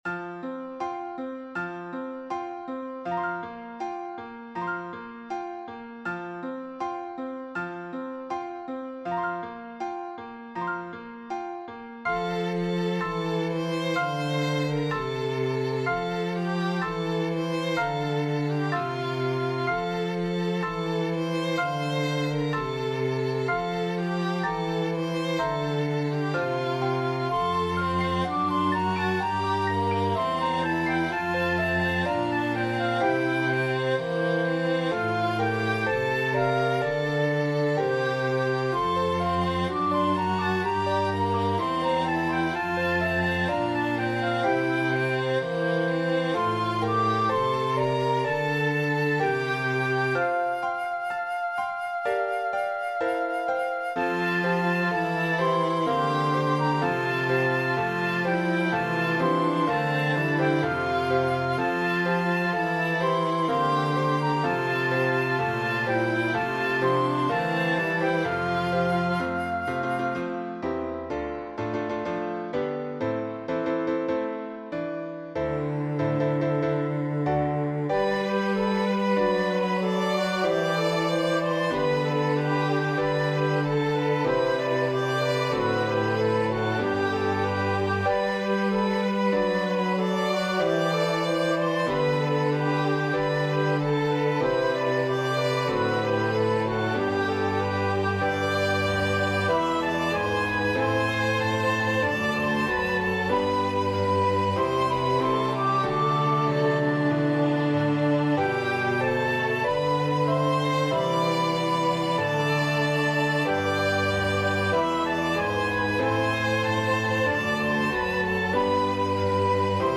Voicing/Instrumentation: SAT We also have other 62 arrangements of " Angels We Have Heard on High ".